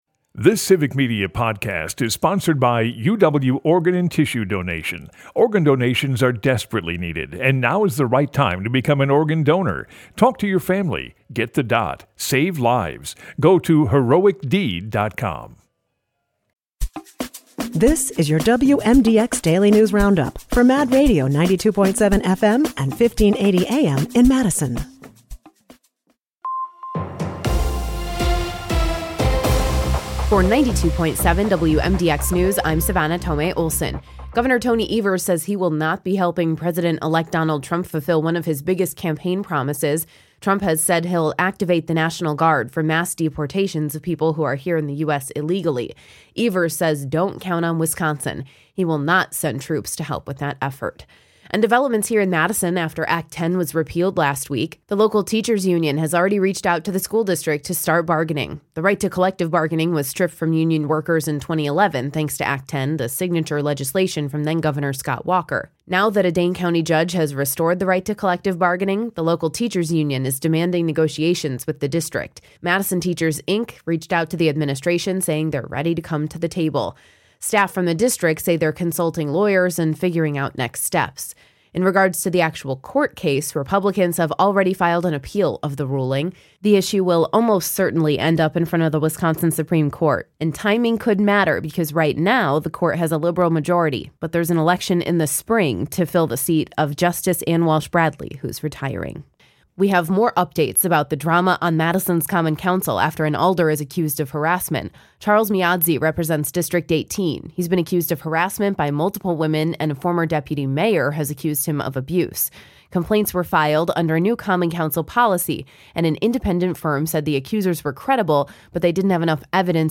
wmdx news